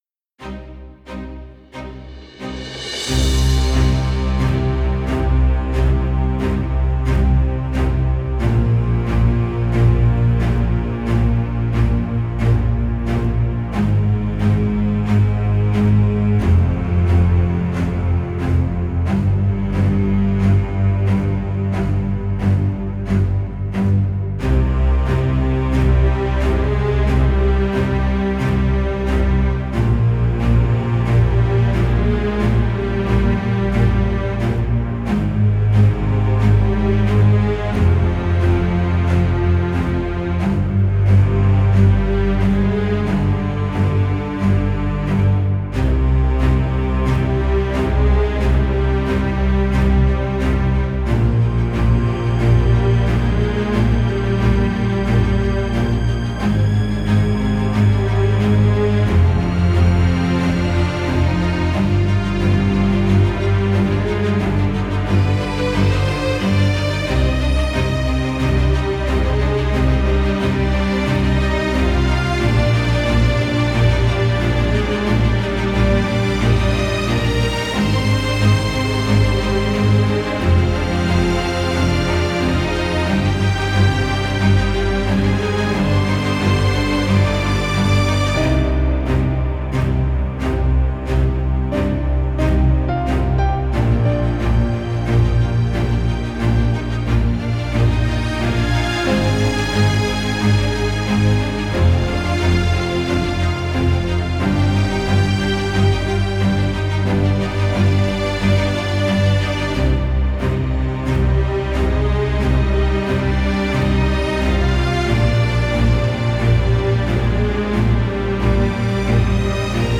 Трек размещён в разделе Русские песни / Киргизская музыка.